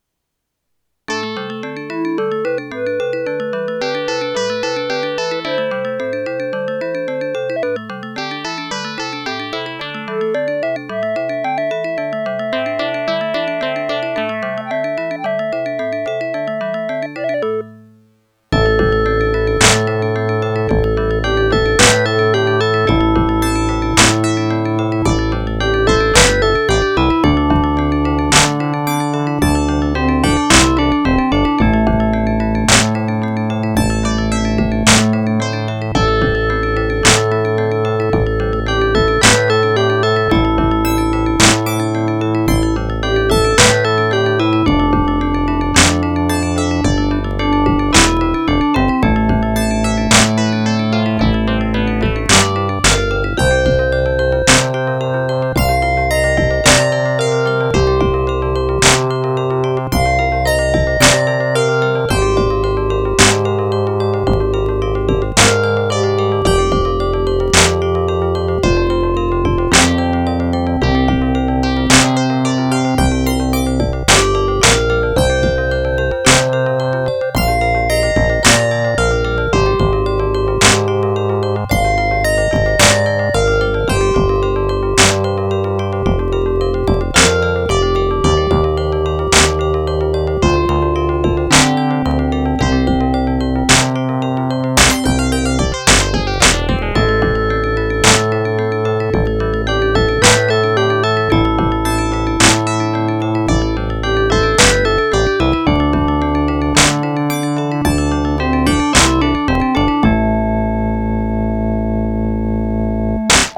All recordings are from the Speaker-Out.